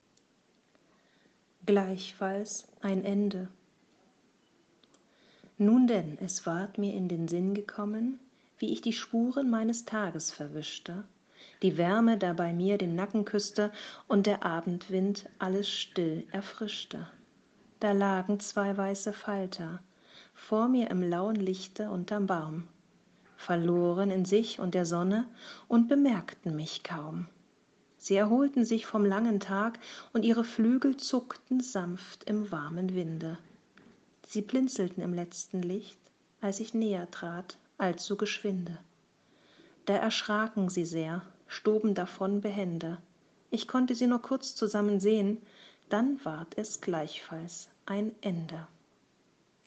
Ich lese auch vor
Meine Gedichte zum Hören